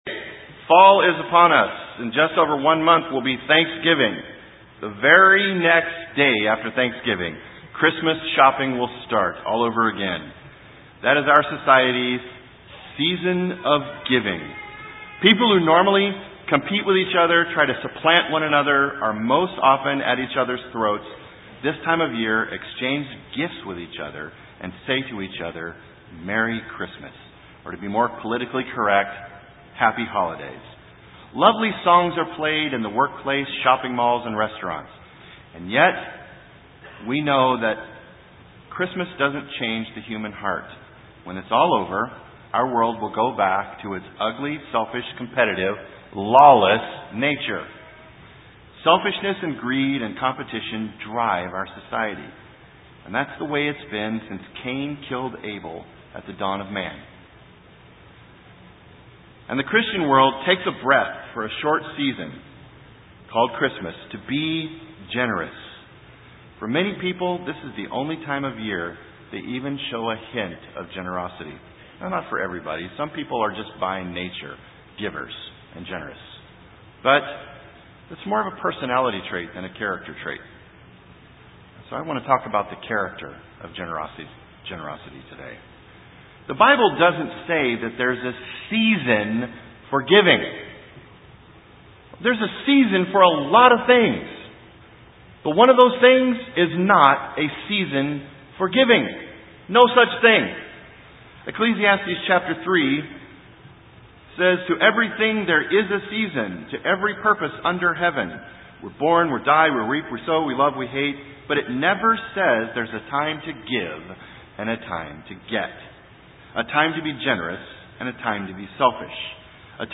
We have a responsibility to give as we are able and be generous in our giving. This sermon help us understand how proper giving can actually bring happiness and joy to our lives.